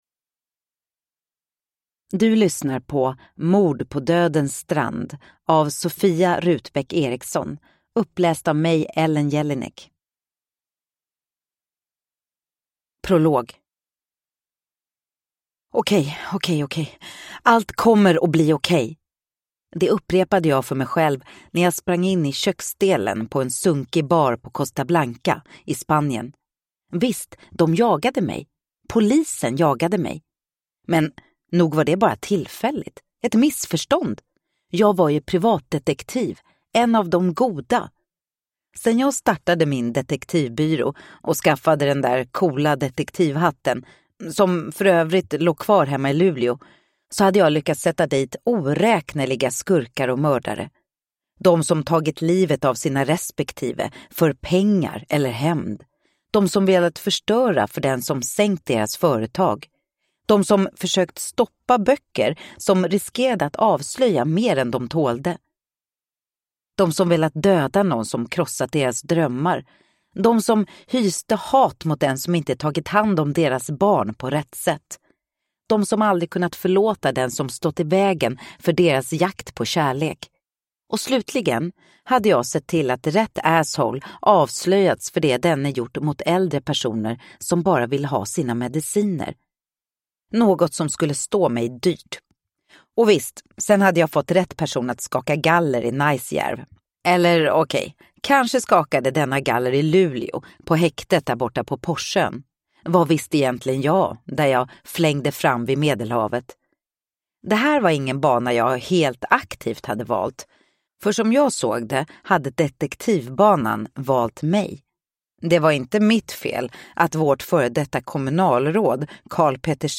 Mord på dödens strand (ljudbok) av Sofia Rutbäck Eriksson